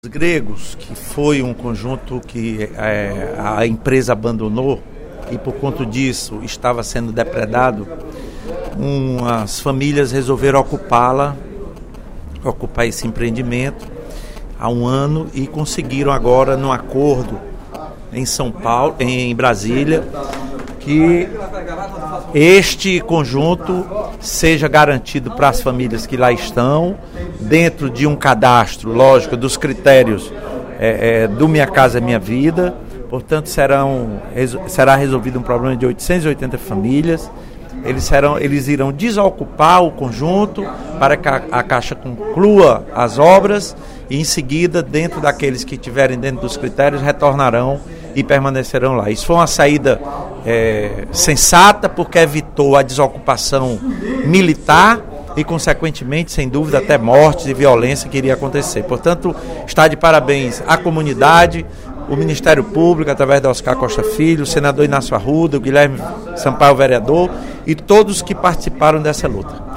Em pronunciamento durante o primeiro expediente da sessão plenária desta terça-feira (13/08), o deputado Lula Morais (PCdoB) informou que começa amanhã o processo de desocupação dos apartamentos do programa Minha Casa, Minha Vida do bairro Jurema, em Caucaia.